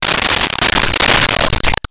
Splash Soft
Splash Soft.wav